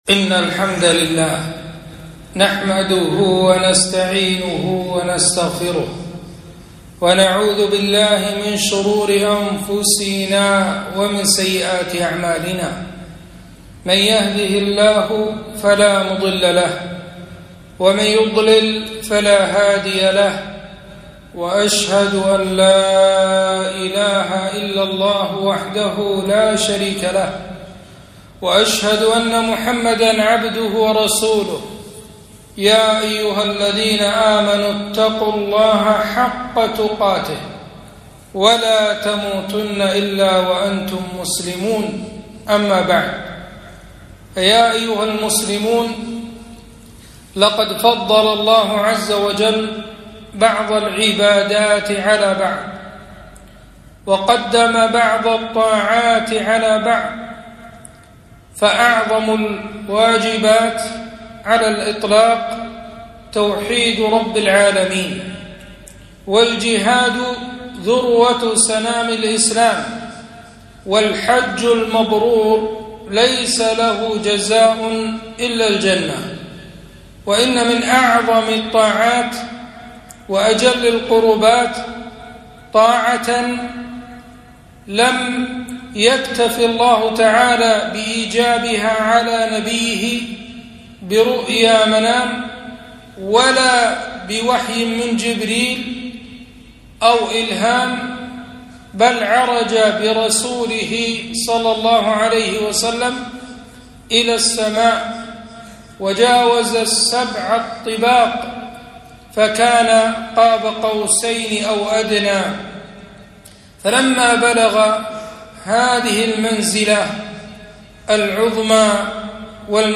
خطبة - الصلاة نور